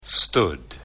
stood /1/ /stʊd/ /stʊd/